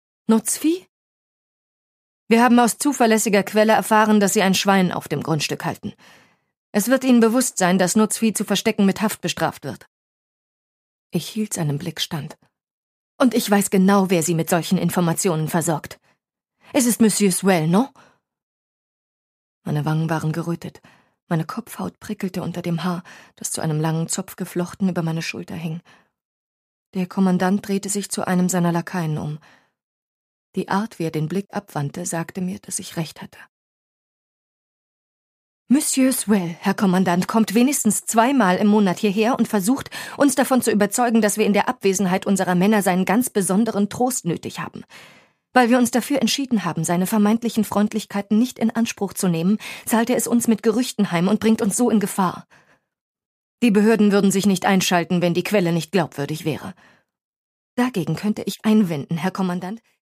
Produkttyp: Hörbuch-Download
Fassung: Autorisierte Lesung